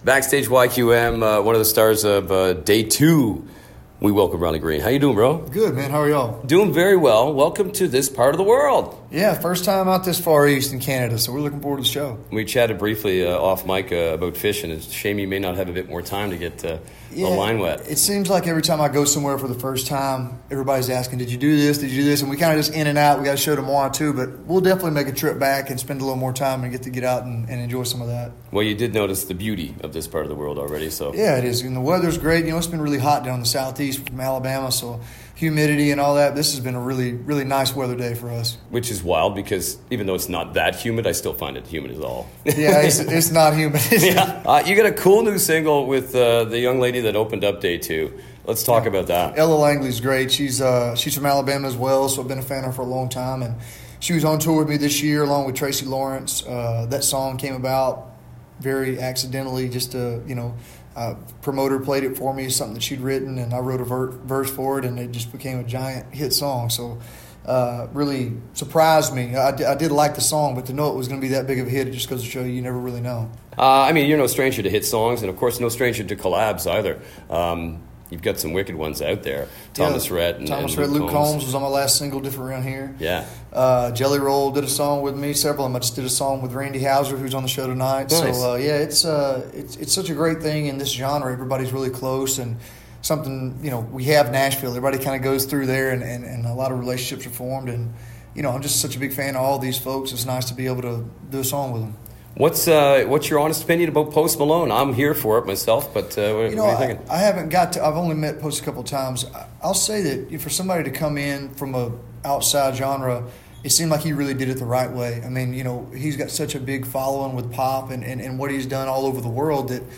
Had a chance to chat with him backstage at YQM before he rolled out and on stage to perform his hits AND his latest collab with the lovely Ella Langley.